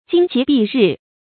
旌旗蔽日 jīng qí bì rì 成语解释 旌旗：旗帜的通称，这里特指战旗。